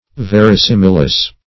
\Ver`i*sim"i*lous\